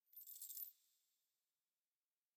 footstep_ghost_3.ogg